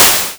Touhou-SFX - A collection of Touhou-like and 2hu relevant audio that I've collected as I went about dev-ing games.
mook_death_bright.wav